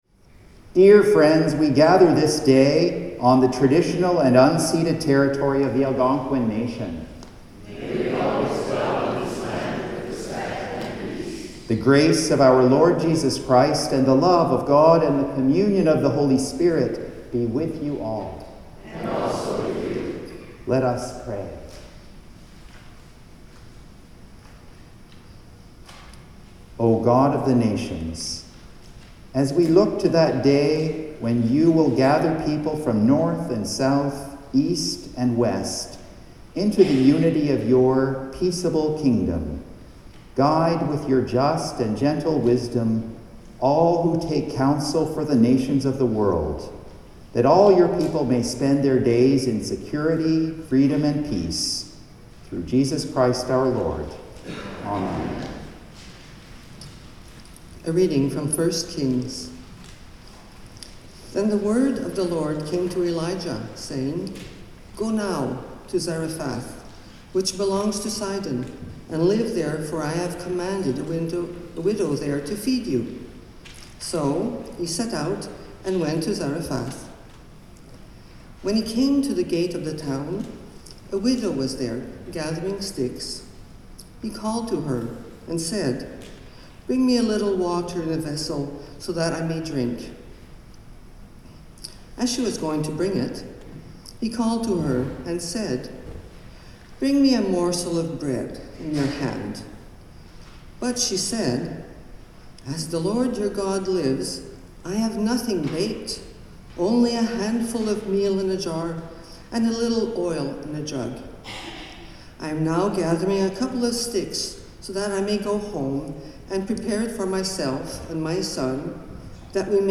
Hymn 572: Let There Be Light
Sermon
The Lord’s Prayer (sung)